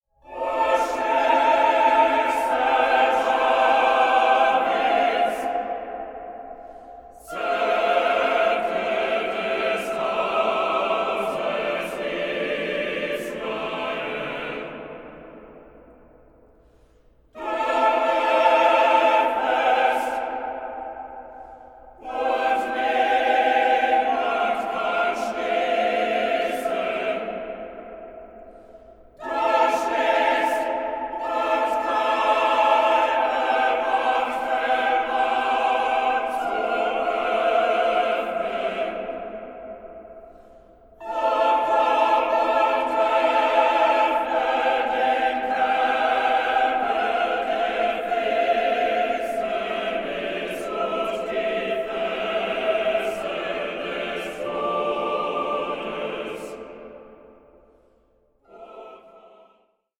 contemporary Scandinavian and Baltic choral music